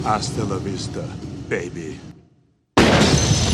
One of the most iconic Arnold Schwarzenegger quotes.